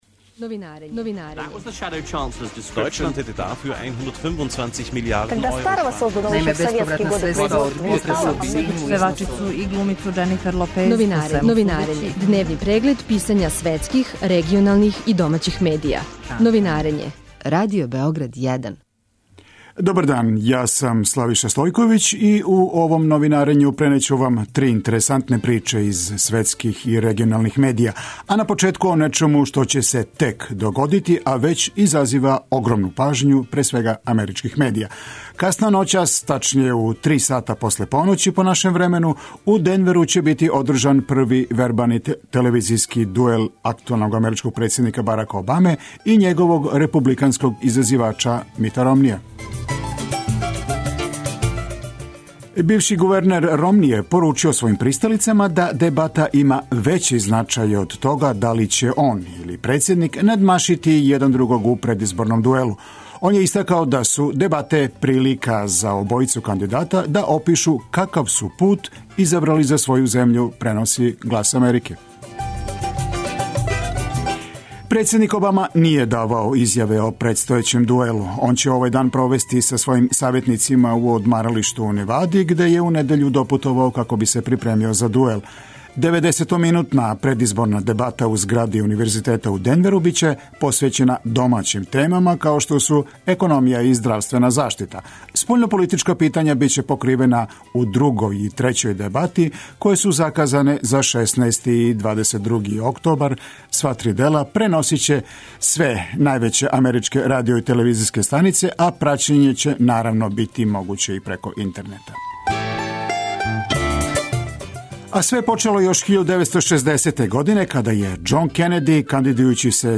Преглед штампе у трајању од 15 минута. Чујте које приче су ударне овога јутра за највеће светске станице и листове, шта се догађа у региону и шта пише домаћа штампа.